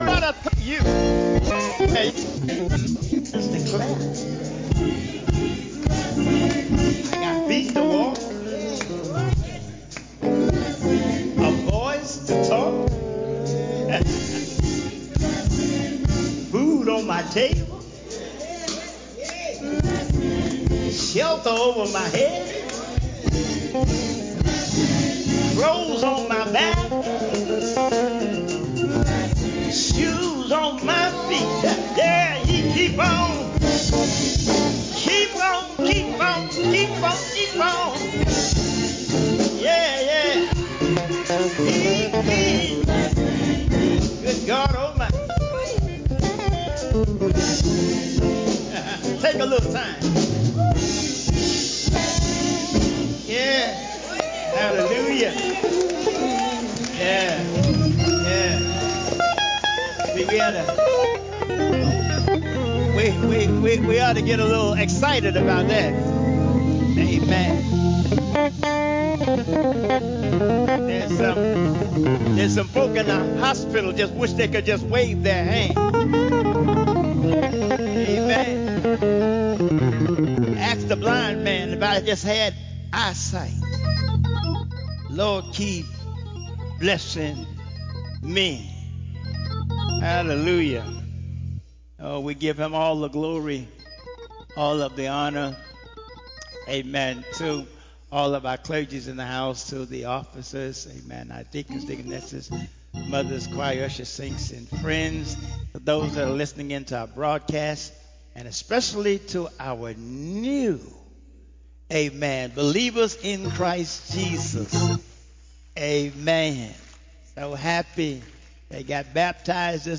10:45 A.M. Service: The Hope of Christmas (Part 1)